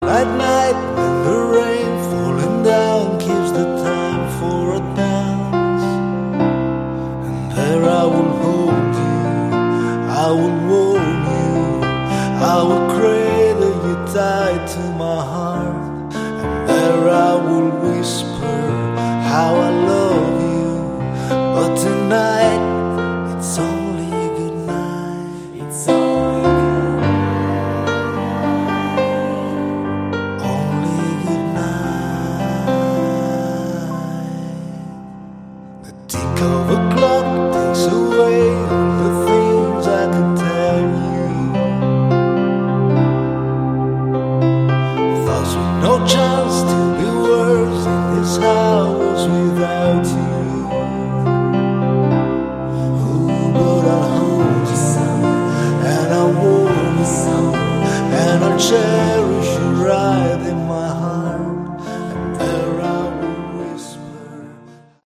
Category: AOR/Melodic Rock
It's simply good, straight ahead rock.